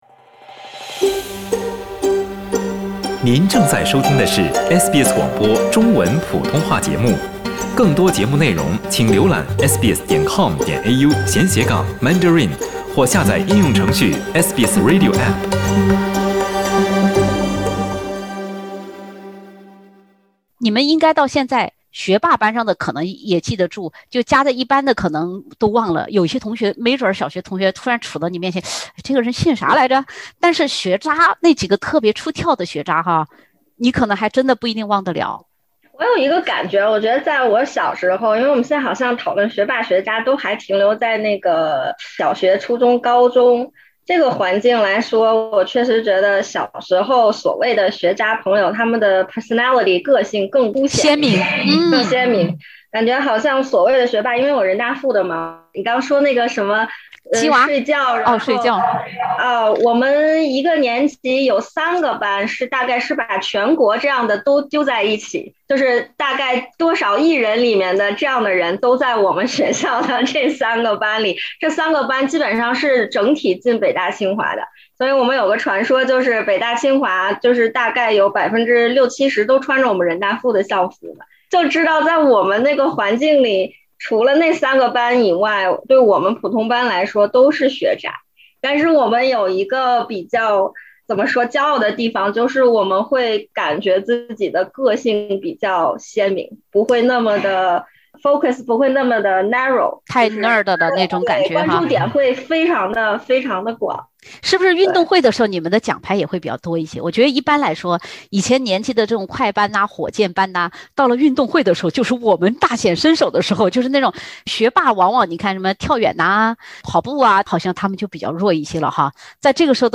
当学生时代，居高不下的荷尔蒙与学校、家长和老师的诸多限制，还有升学压力对撞时，班上学渣们也许给我们曾经的苦读生涯多多少少增添了一抹亮丽的色彩。(点击封面图片，收听有趣谈话）